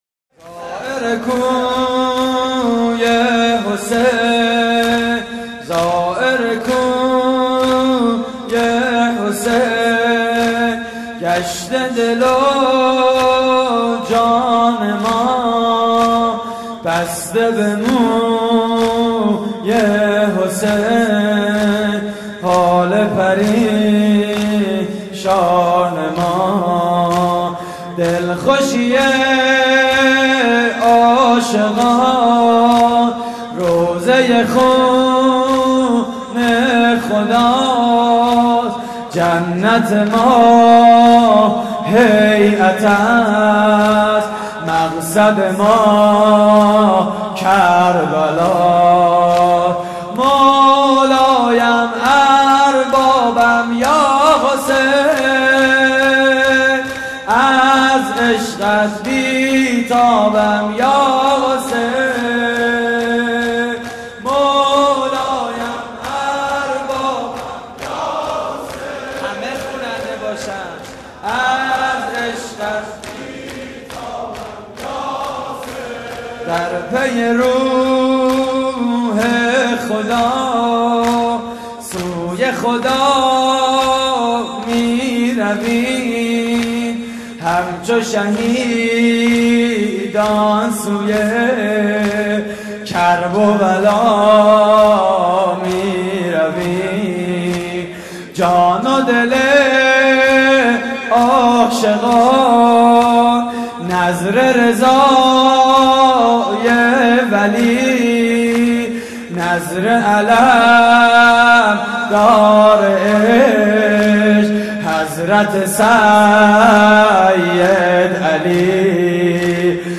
شور: زائر کوی حسین
مراسم عزاداری شب دوازدهم (محرم 1433)